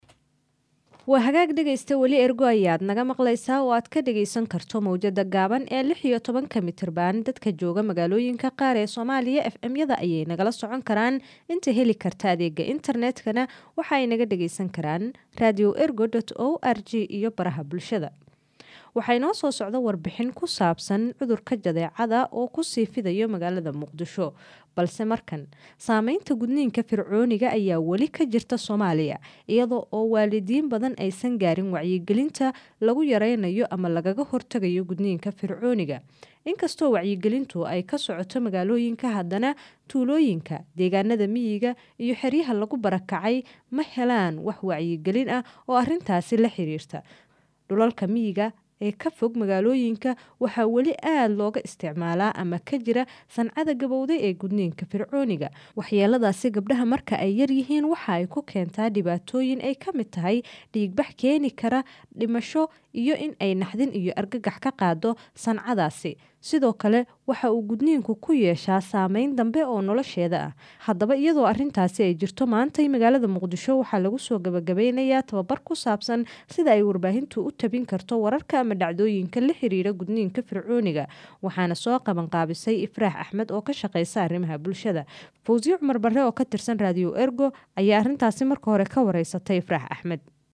Wareysi: Wacyigelinta gudniinka fircooniga wax maka bedeshay dhibaatada gabdhaha loo geysto?